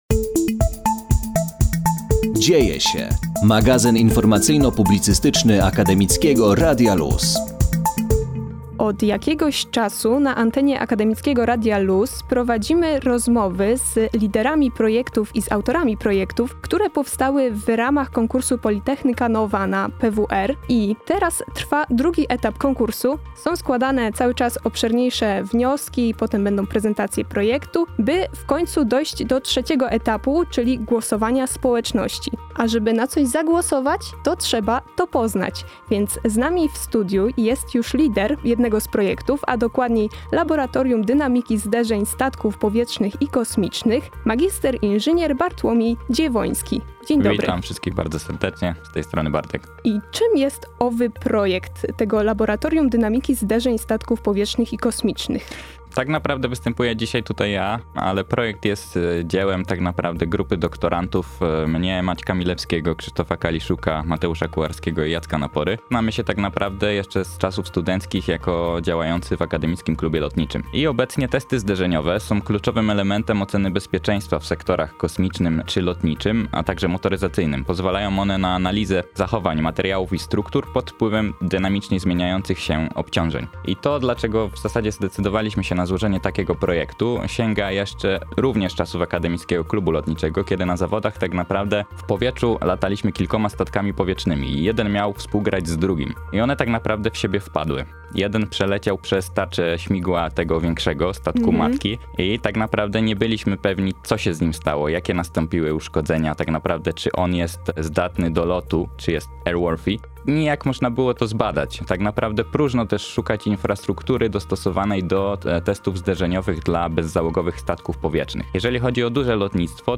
POLYTECHNICA NOVA – wywiady z laureatami pierwszego etapu konkursu
Wraz z nim ruszył nasz cykl rozmów z przedstawicielami zakwalifikowanych wniosków na antenie Akademickiego Radia Luz.